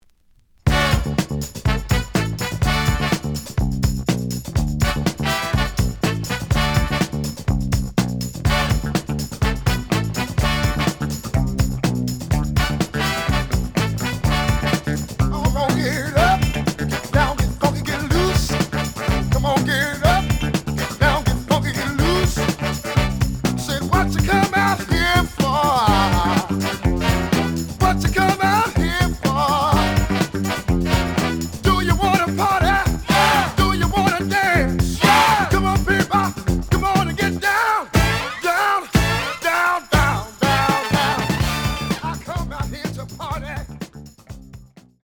The audio sample is recorded from the actual item.
●Genre: Soul, 70's Soul